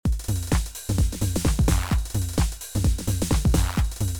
Step 4: Adding Pattern D, Hi-Hat Rolls & Snare Flam
After some programming, here’s variation D looped over two bars, as in the previous step, for reference purposes:
You should also notice we programmed substeps on the clap for the last two steps.
We have added flam to the SNARE DRUM on beats 8, 9, 10, 12.
Lastly, we added a ride cymbal on every off-beat.